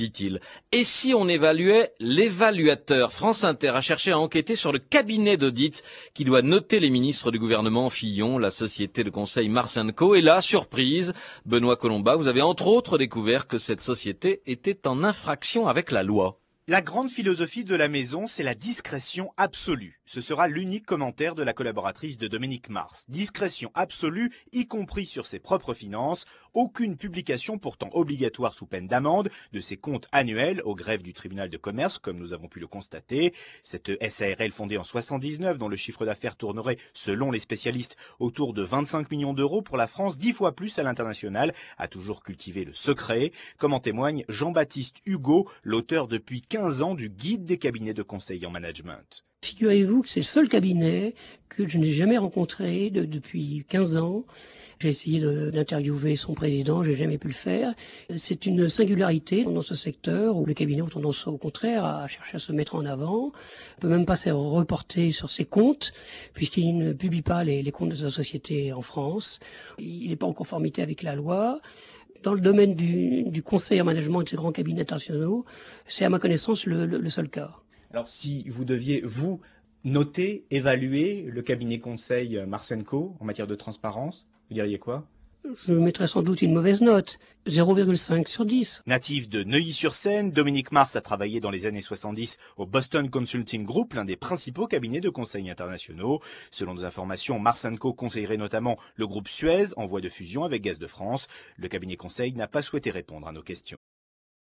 un reportage
diffusé sur France Inter dans le journal de 8h du 11/01/2008 révèle que ce cabinet ne publie pas, comme c’est pourtant obligatoire, ses comptes annuels au greffe du tribunal de commerce.